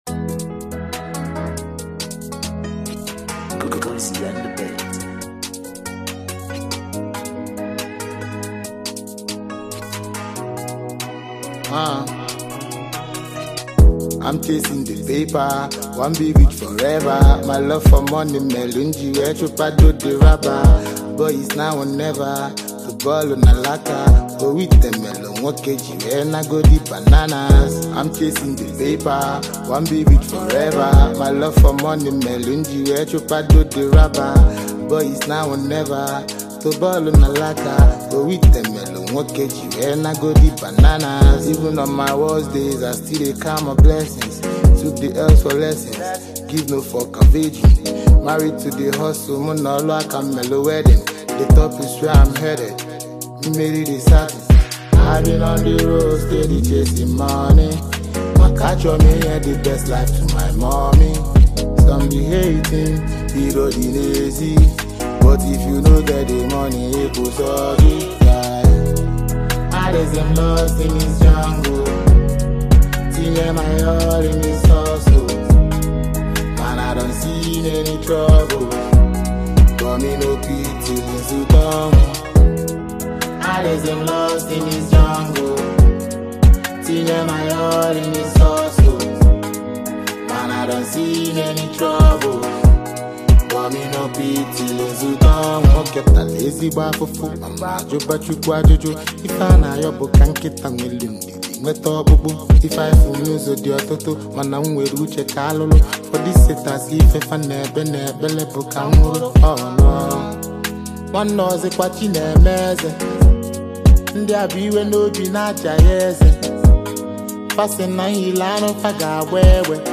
Talented Nigerian rapper and lyricist
honest storytelling and smooth flow